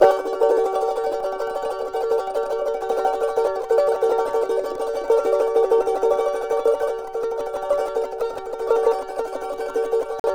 CHAR G#MN TR.wav